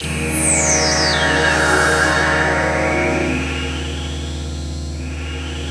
17PAD 01  -R.wav